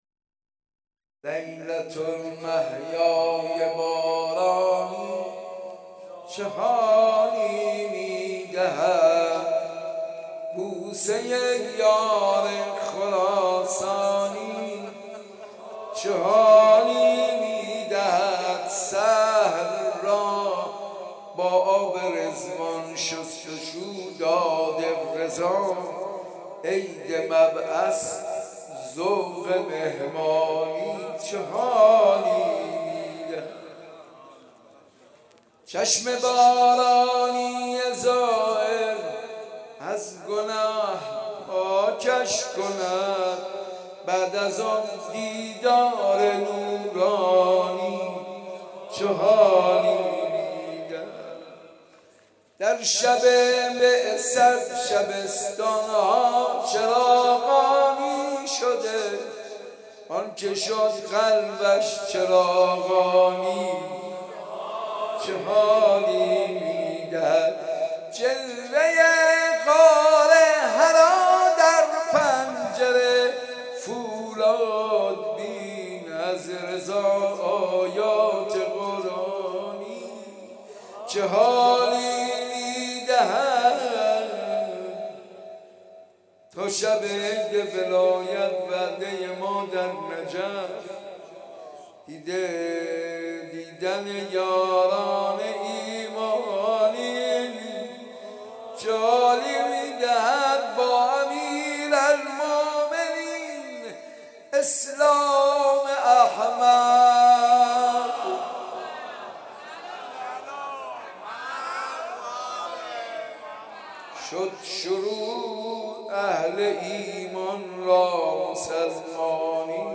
گلچین مداحی های ویژه مبعث پیامبر اکرم(ص)